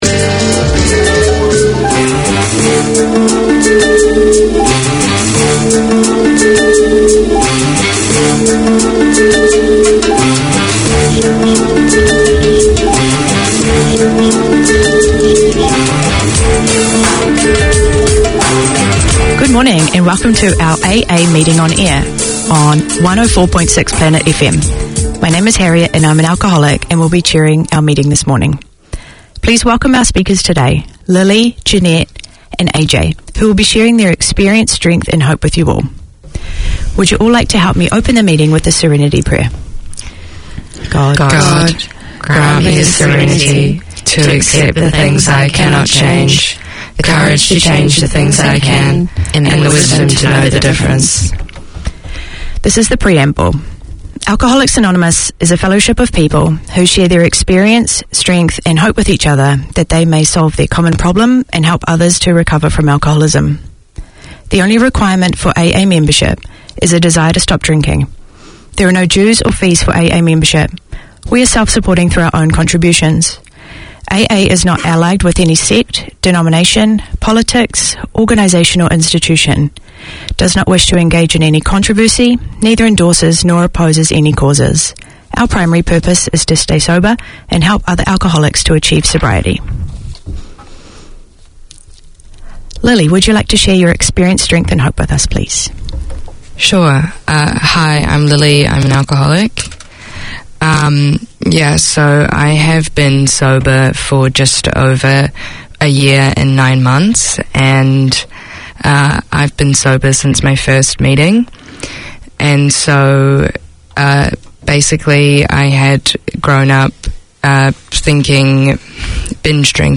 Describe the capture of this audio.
This show talks about science topics and their relevance to our everyday lives in a language that is understandable to the person on the street. Fascinating discussions are delivered along with ‘hot-off-the-press’ science news and a curious selection of the favourite music of scientists.